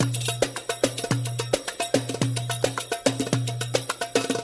描述：阿拉伯语的西部达布卡
Tag: 108 bpm Ethnic Loops Drum Loops 1.12 MB wav Key : Unknown